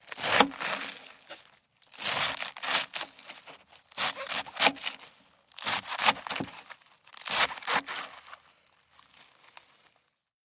Звуки капусты
Звук нарезки капусты на деревянной доске